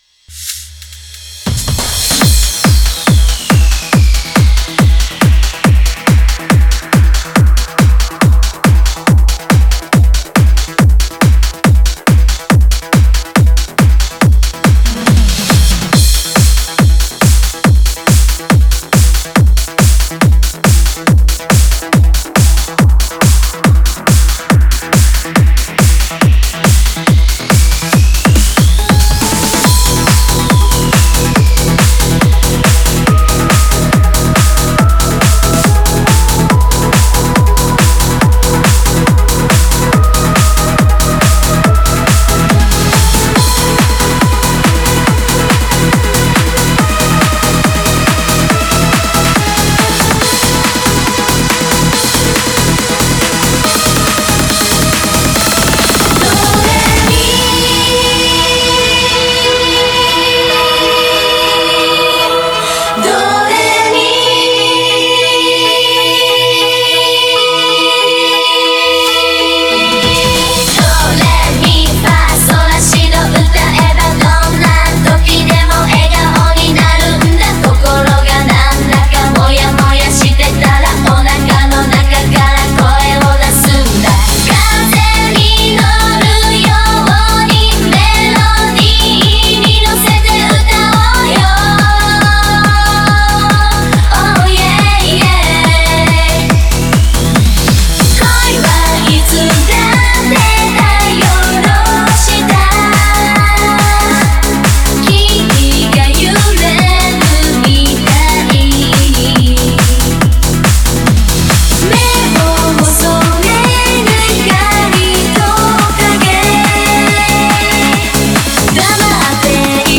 Genre(s): Trance